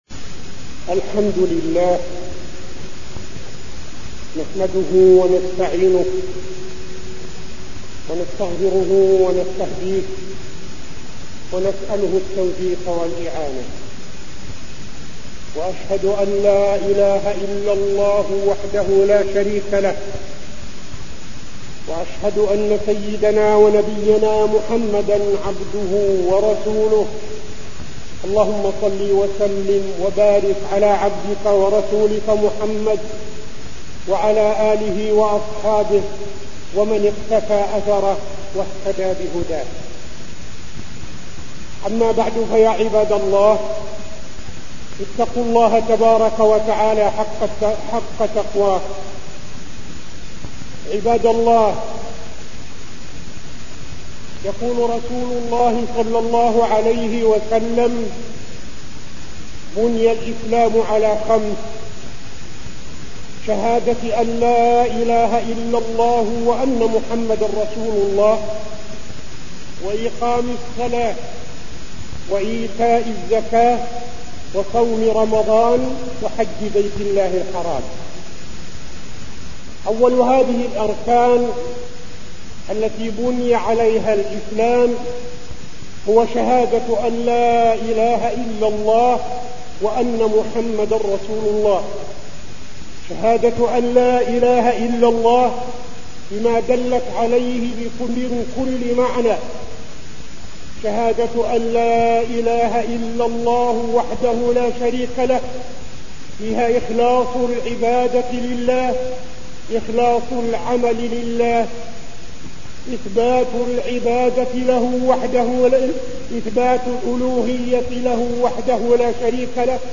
تاريخ النشر ٢ رجب ١٤٠٣ هـ المكان: المسجد النبوي الشيخ: فضيلة الشيخ عبدالعزيز بن صالح فضيلة الشيخ عبدالعزيز بن صالح أهمية الصلاة